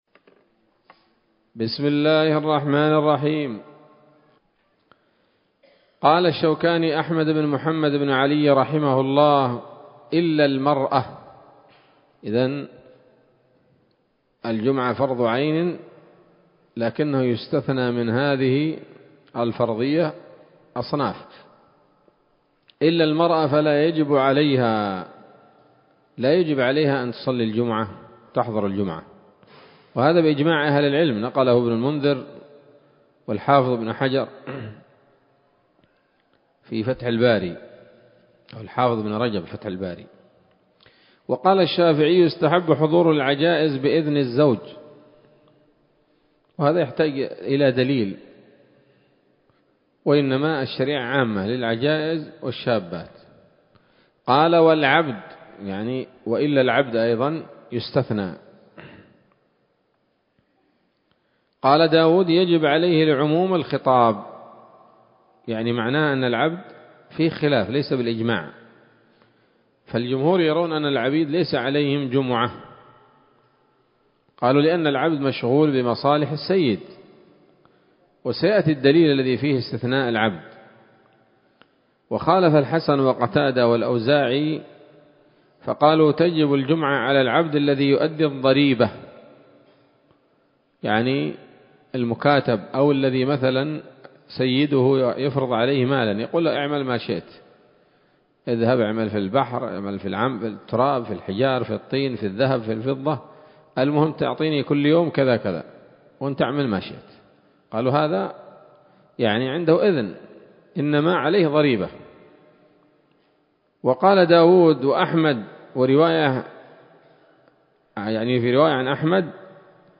الدرس الأربعون من كتاب الصلاة من السموط الذهبية الحاوية للدرر البهية